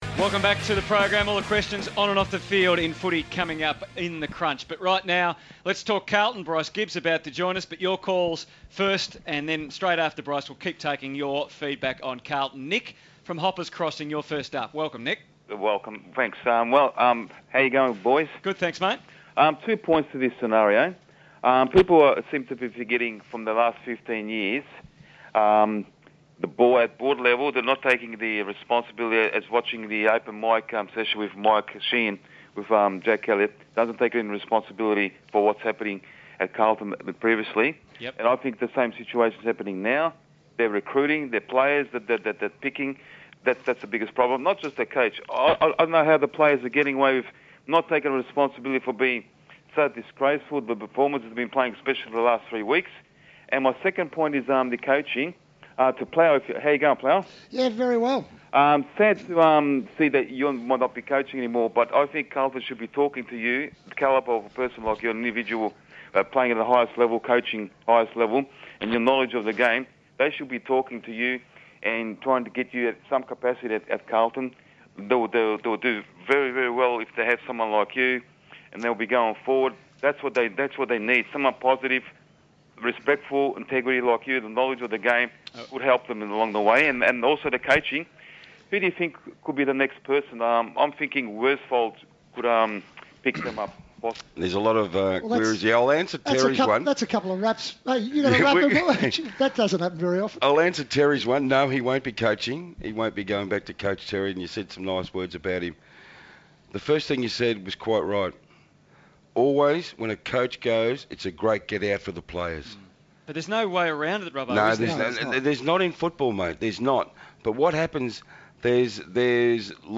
Crunch Time Segment Two: Carlton discussion including Bryce Gibbs interview